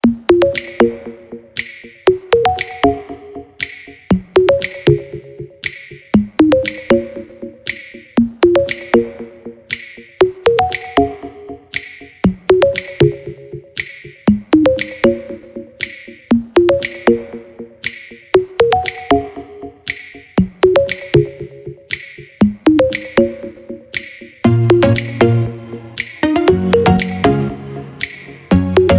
приятные мелодичные
без слов